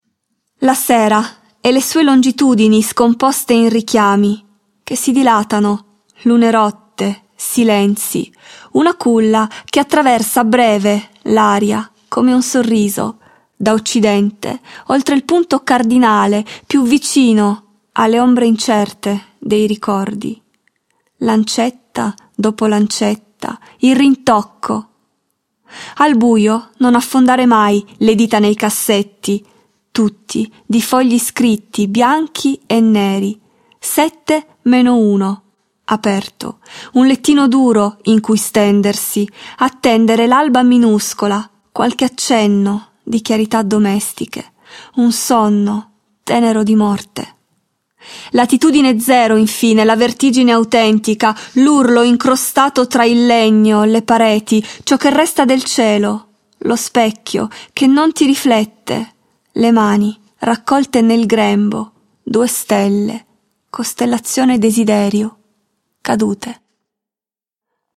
Newsletter » Poesia » Archivio voci poesia
ASCOLTA LA VOCE DELL'AUTRICE